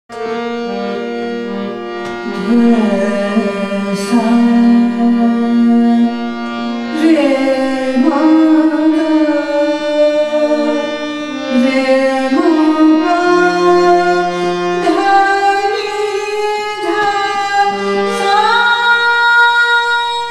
Raga
ThaatKhammaj
Aroha‘D S, R m G, R m P D n D S’
Jhinjhoti (Aroha)